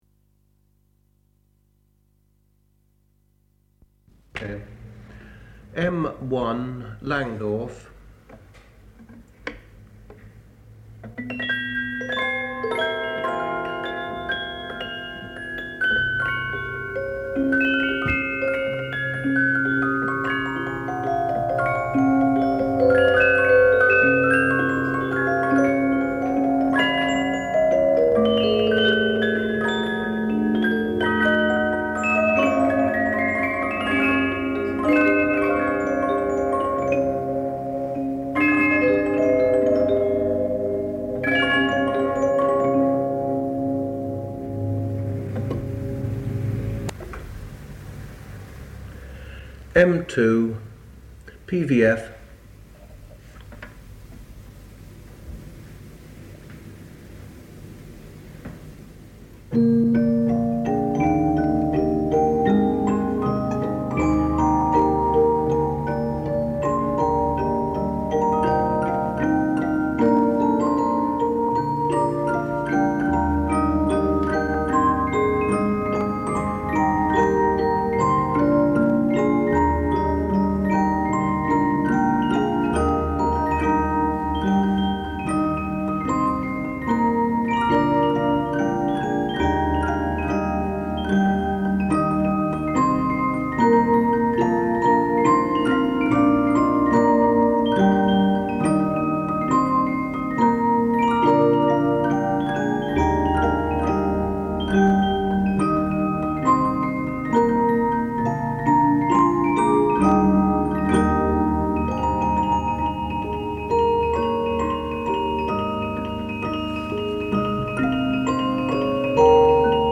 Musical boxes and cylinders
Musical boxes and cylinders: recording of European musical boxes and cylinders in the Pitt Rivers Museum's collections being played, including English, German and Swiss examples.
From the sound collections of the Pitt Rivers Museum, University of Oxford, being one of a small number of recordings of the musical instruments in the institution's collections being played or discussed.